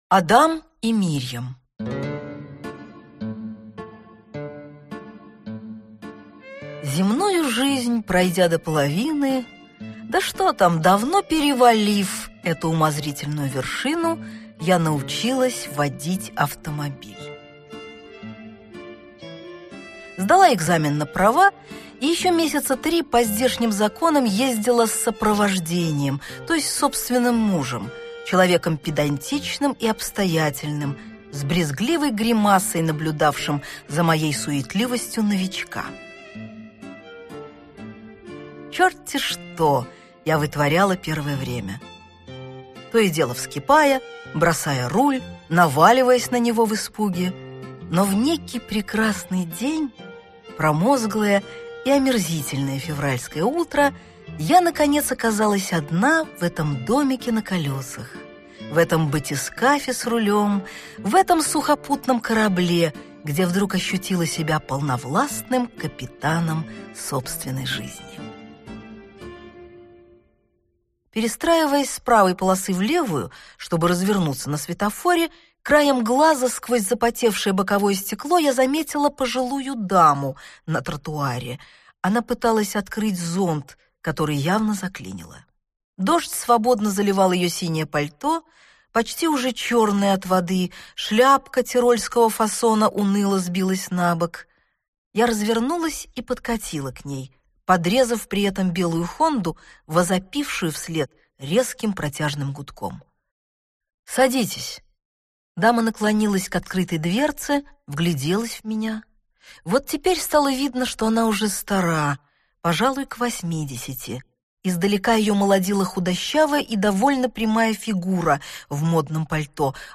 Аудиокнига Адам и Мирьям | Библиотека аудиокниг
Aудиокнига Адам и Мирьям Автор Дина Рубина Читает аудиокнигу Дина Рубина.